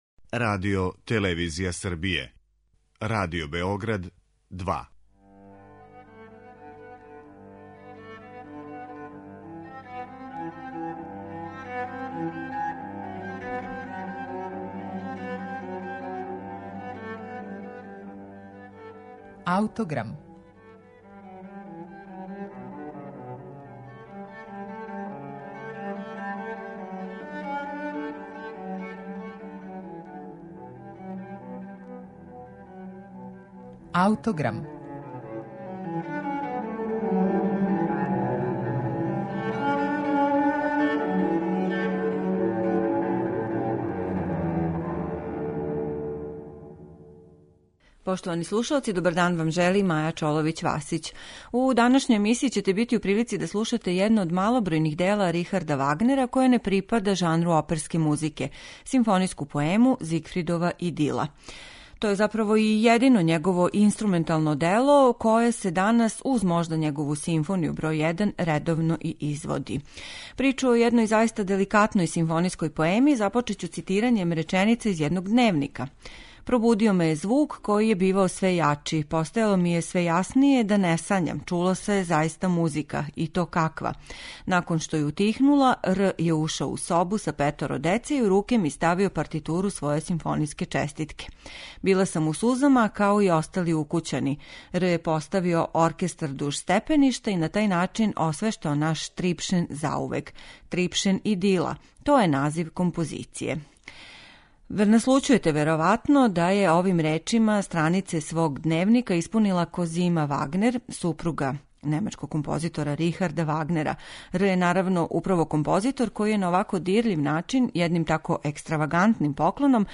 Диригент је Серђу Челибидаке.